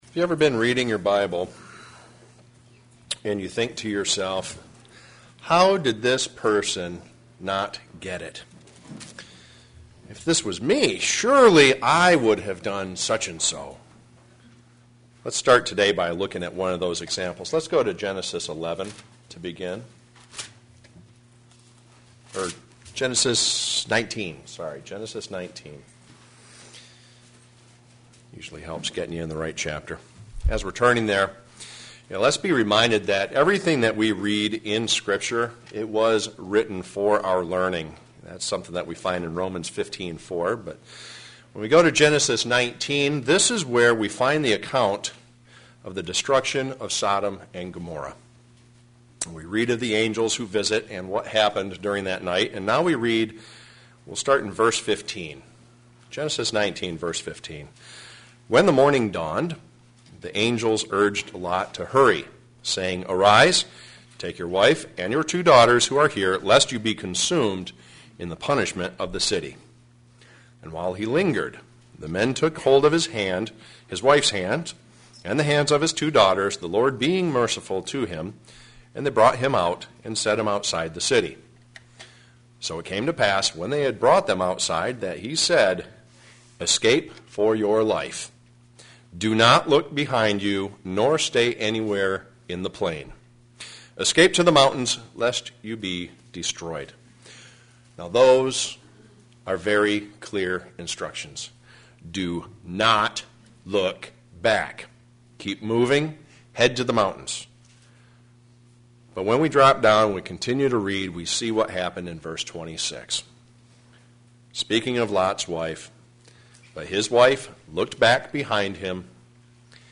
Remember what happened to Lot's wife when she looked back. sermon Studying the bible?